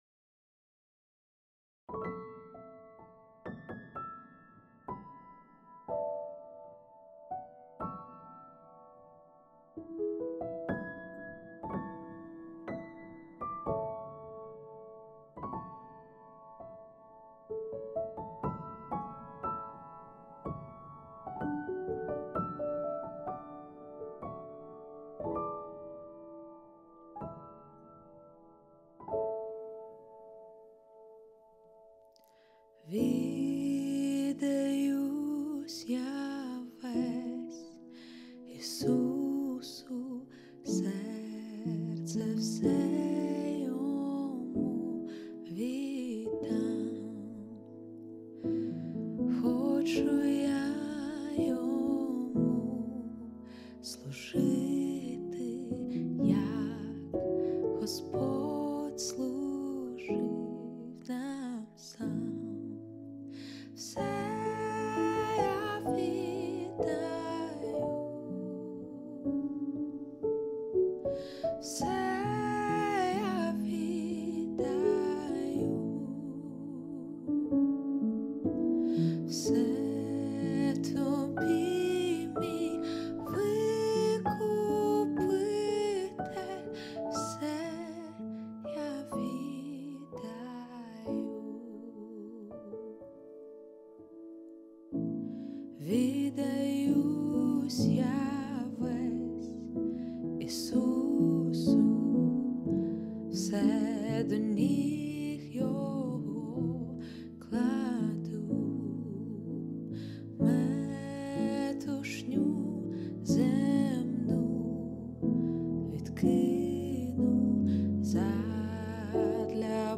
2037 просмотров 466 прослушиваний 30 скачиваний BPM: 90